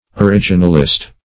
Originalist \O*rig"i*nal*ist\, n.